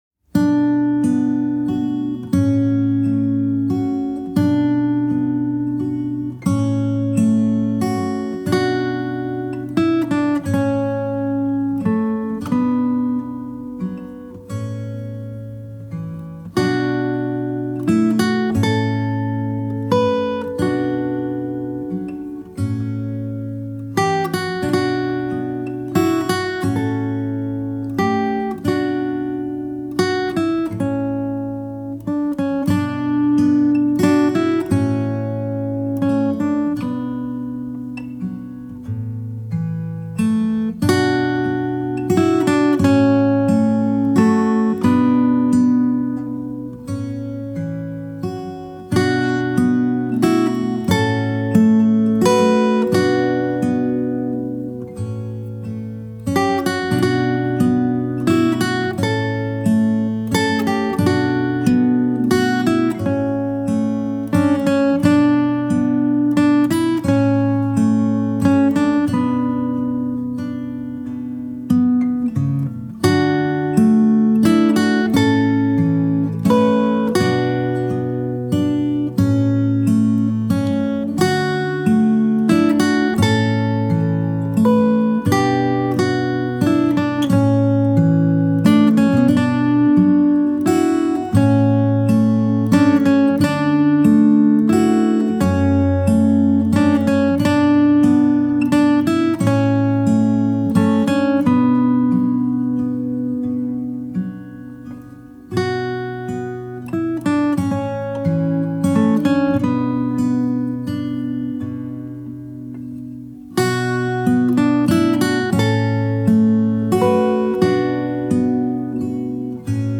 آرامش بخش
عصر جدید , گیتار